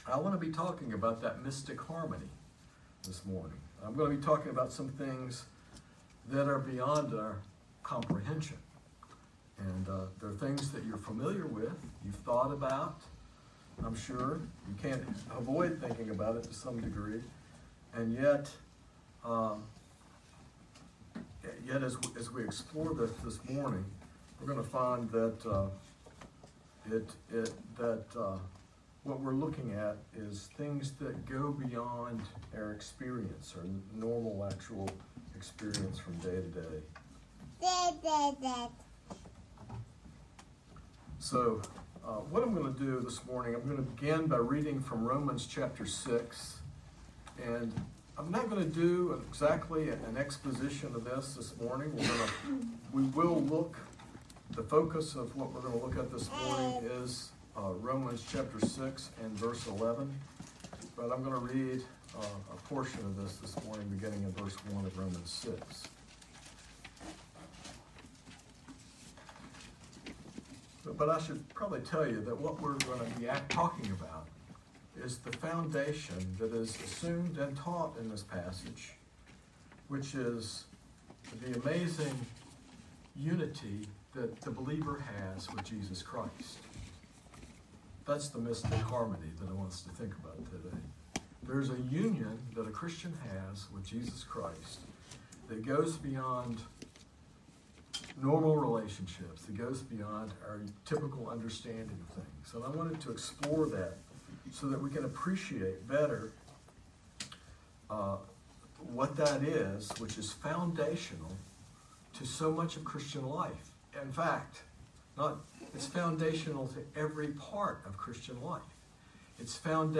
This sermon explores the profound spiritual union believers share with Jesus Christ, a ‘mystic harmony’ that transcends typical understanding. It emphasizes that this union is foundational to every aspect of Christian life and salvation, empowering believers to live a new life dead to sin and alive to God.